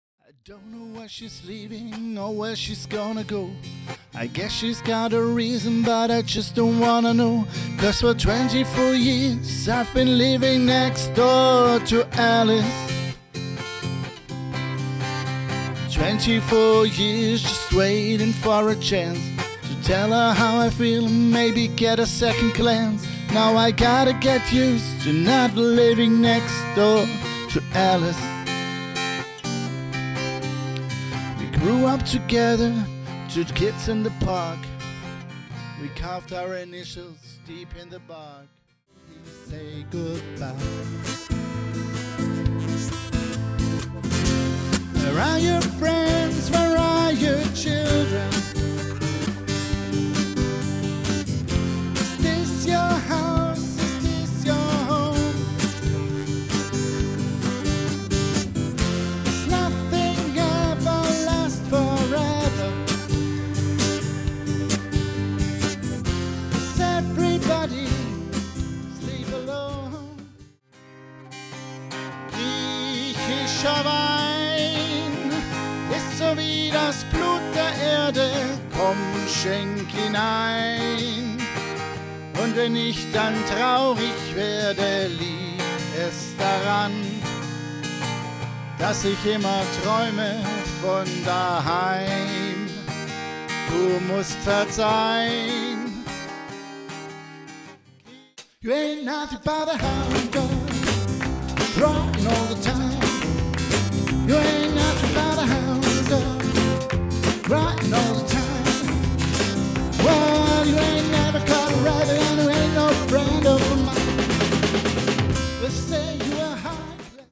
Unplugged - Pure Music